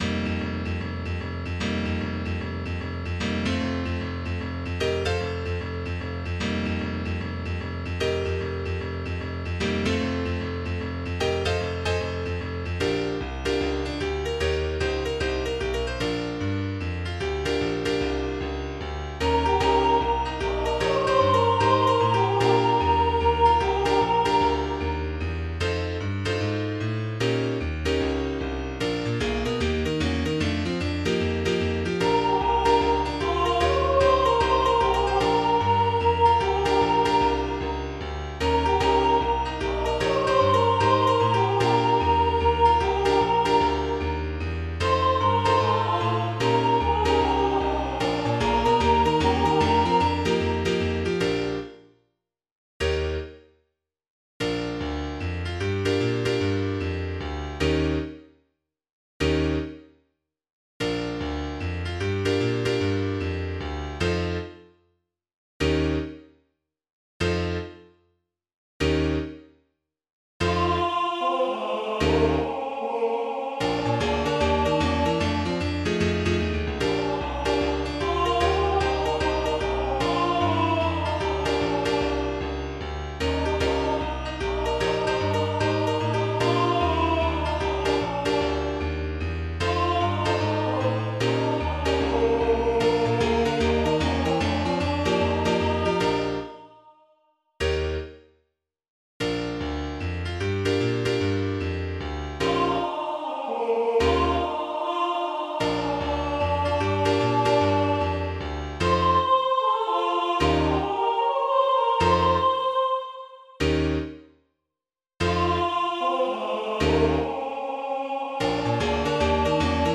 11_Abraham_et_semini_eiusAlt.mp3